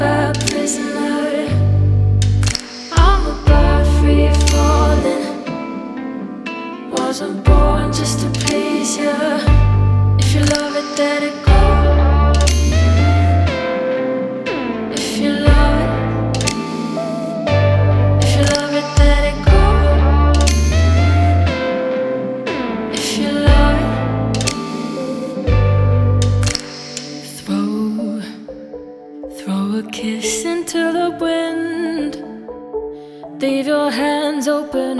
Жанр: Альтернатива / Электроника